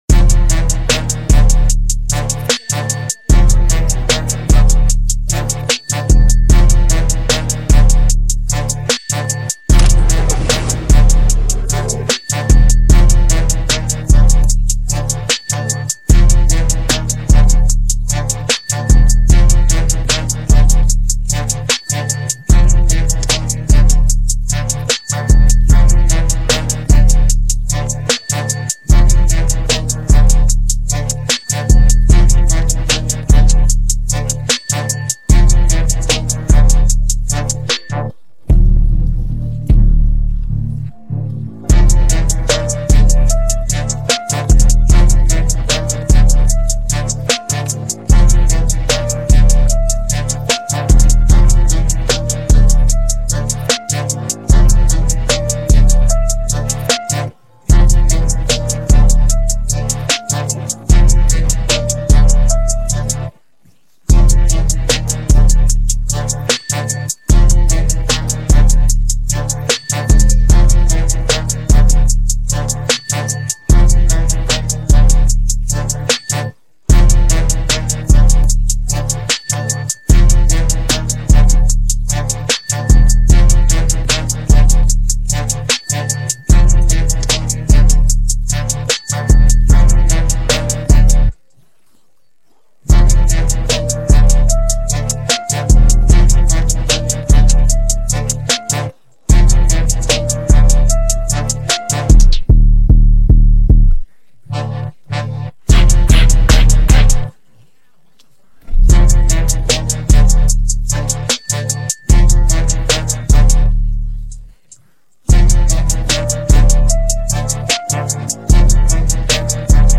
This is the official instrumental